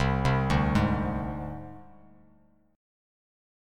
Csus2#5 Chord